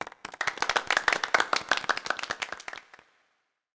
Applause Sound Effect
Download a high-quality applause sound effect.
applause.mp3